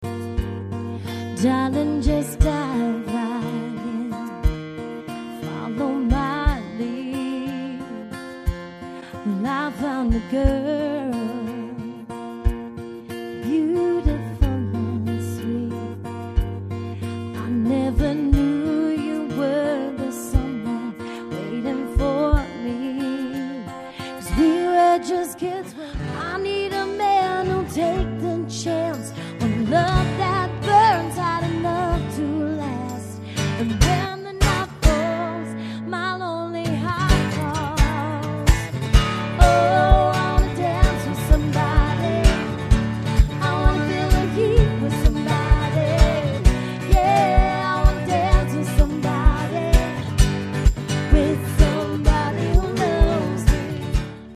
sultry yet powerful vocals
guitar
soulful harmonies